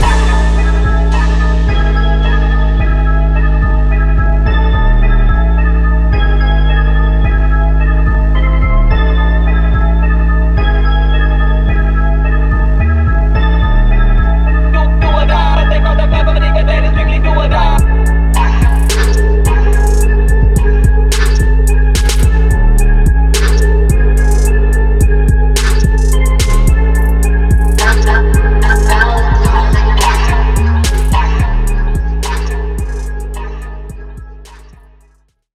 Dark Memphis rap type beat
• 108 BPM